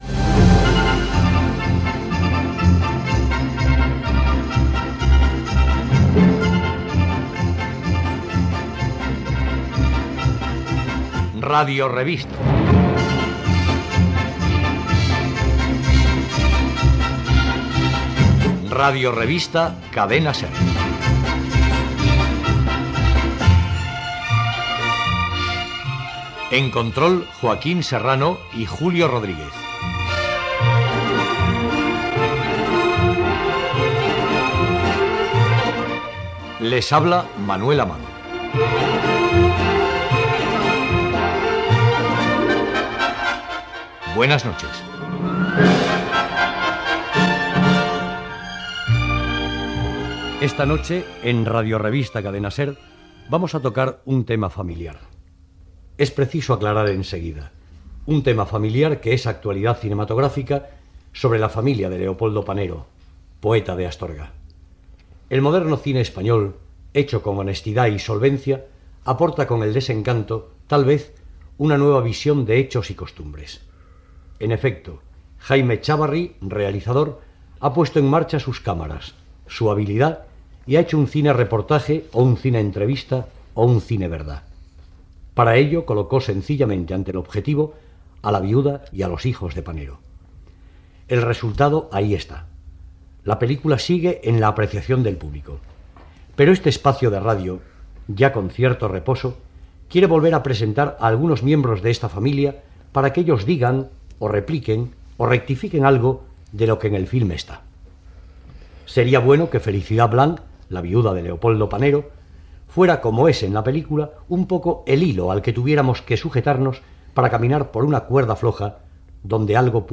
Careta del programa, equip, tema del programa. Entrevista al director de cinema Jaime Chávarri i a la família de Lopoldo Panero: Felicidad Blanc, la seva esposa, i Leopoldo i Michi Panero, els seus fills. S'hi parla de la pel·ícula "El Desencanto", de Jaime Chávarri, dedicada a la vida del poeta d'Astorga Leopoldo Panero.